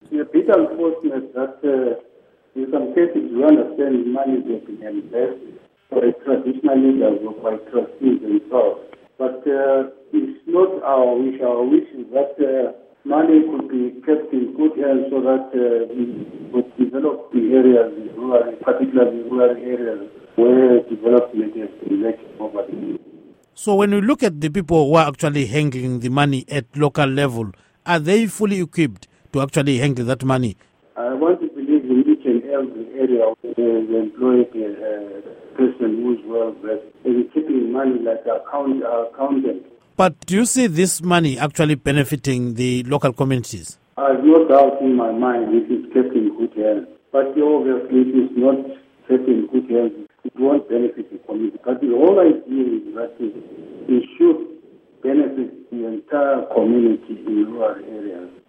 Interview With Chief Mtshana Khumalo